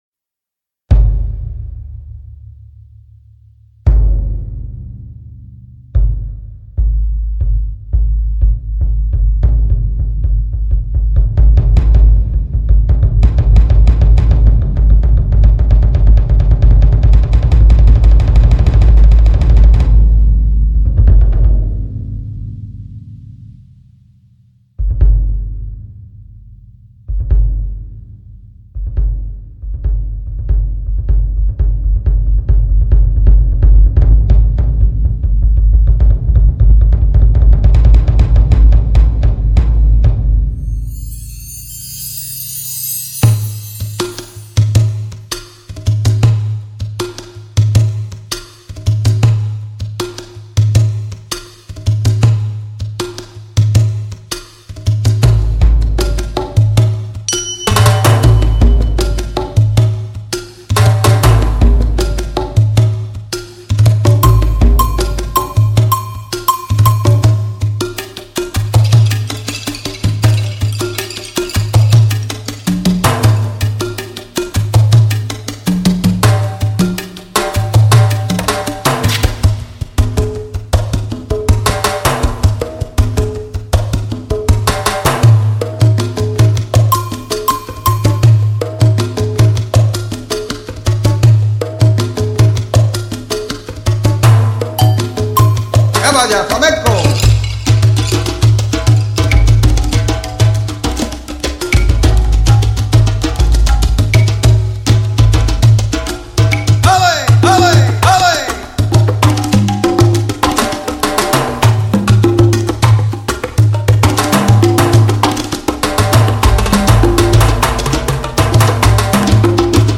专辑格式：DTS-CD-5.1声道
首创国际SRS+WIZOR全方位360°环绕HI-FI AUTO SOUND专业天碟
为低音质MP3